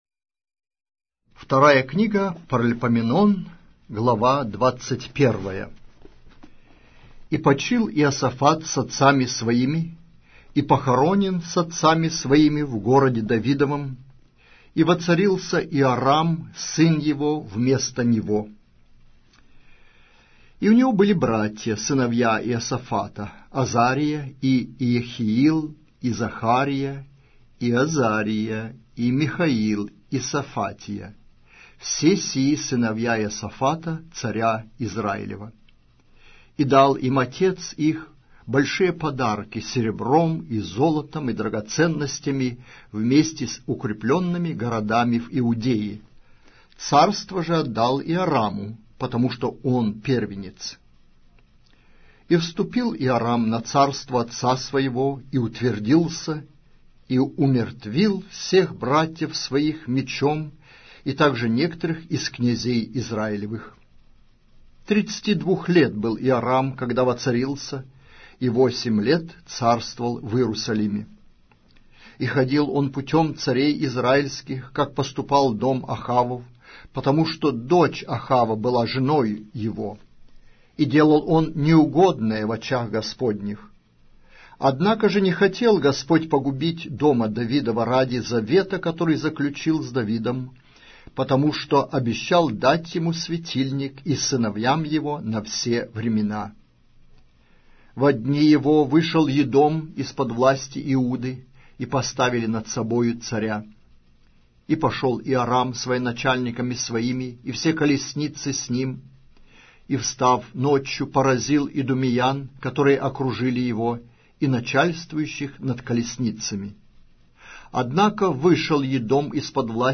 Аудиокнига: 2-я Книга. Паралипоменон